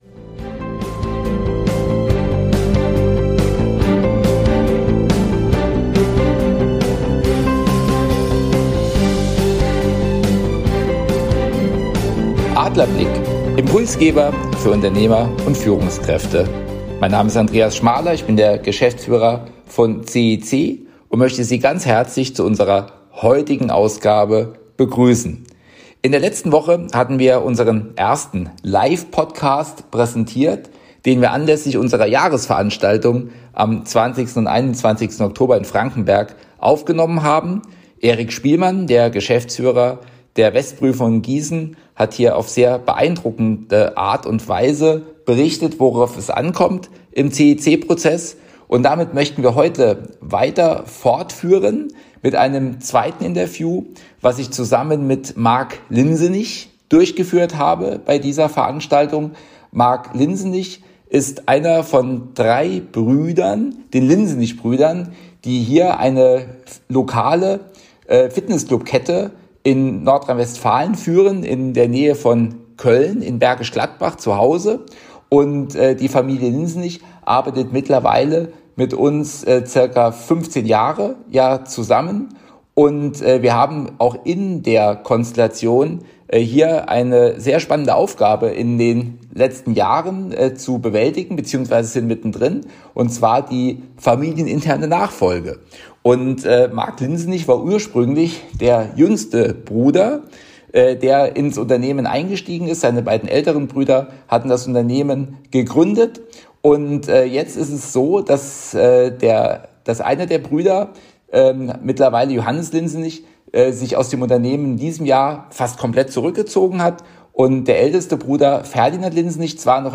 In dem heutigen Podcast handelt es sich um einen weiteren Livepodcast von unserem Jahreskongress am 21.10.2022.